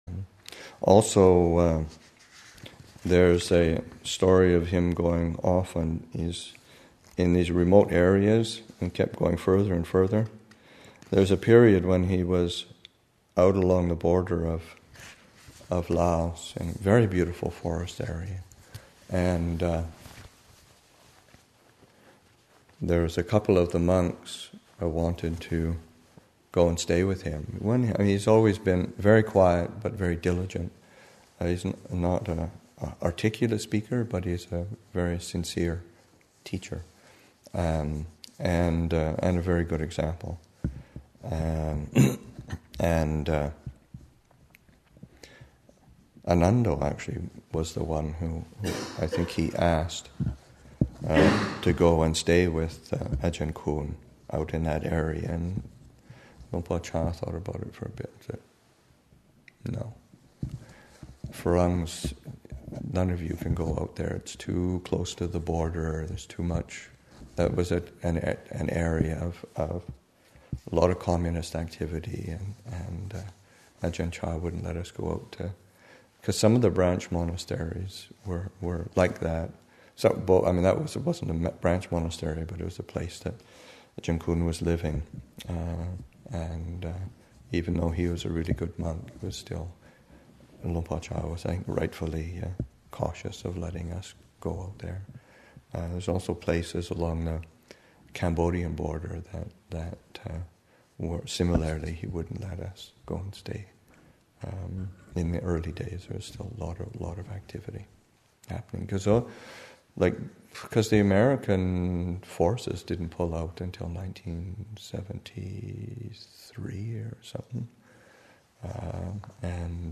Recollection